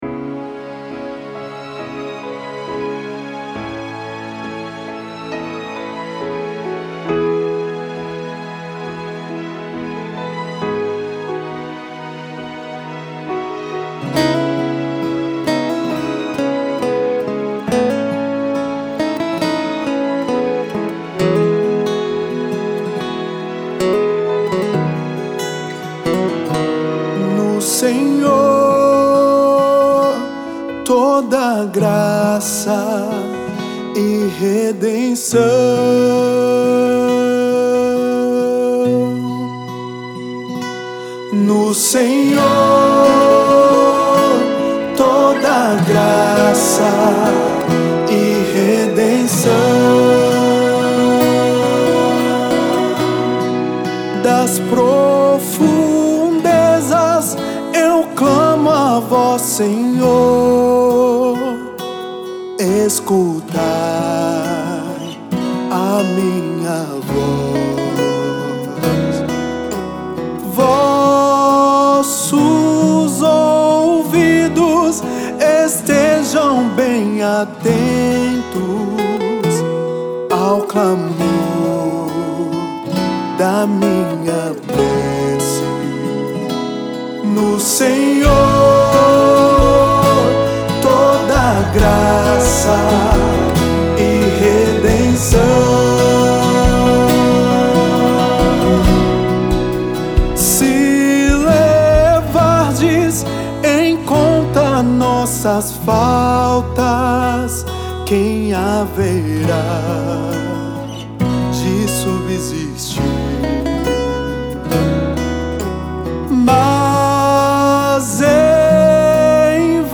5º Domingo da Quaresma
Backing Vocal